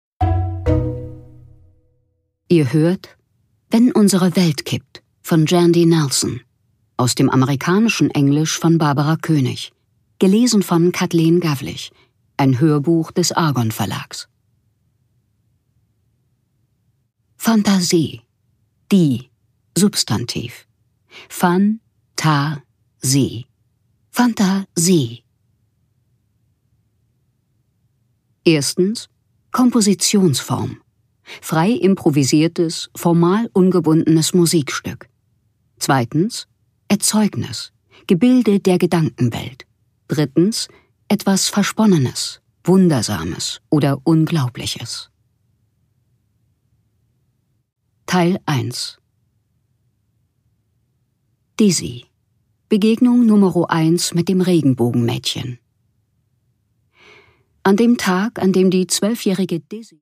Jandy Nelson: Wenn unsere Welt kippt (Ungekürzte Lesung)
Produkttyp: Hörbuch-Download